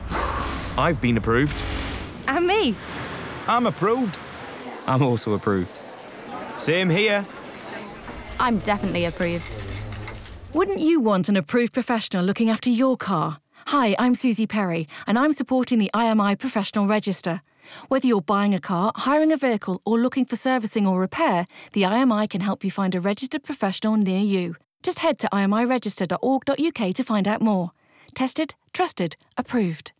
Radio Adverts
Listen to the radio adverts featuring Suzi Perry .